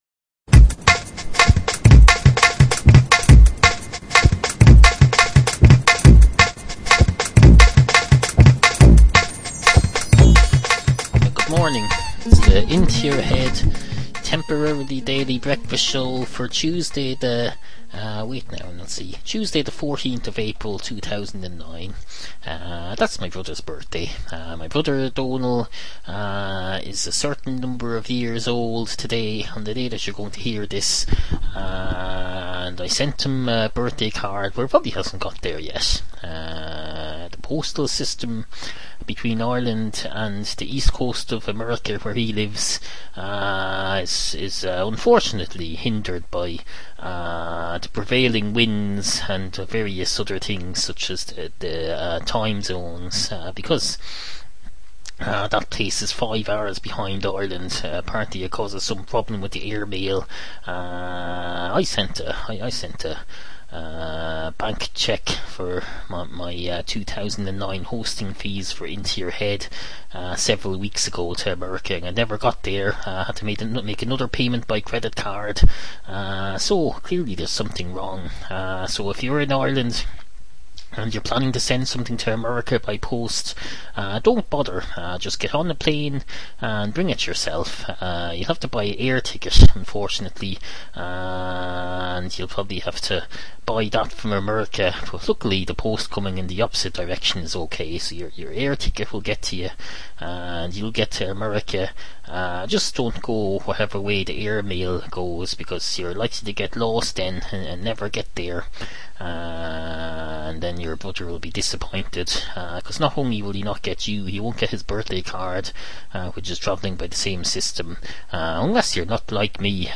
Obscure 21st Century Irish audio comedy series